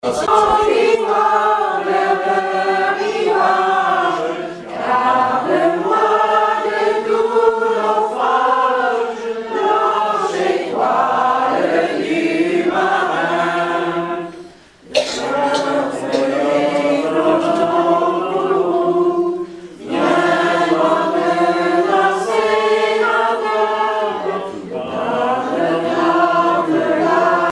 Informateur(s) Club d'anciens de Saint-Pierre association
circonstance : cantique
Collecte de chansons
Pièce musicale inédite